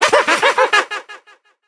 evil_gene_vo_06.ogg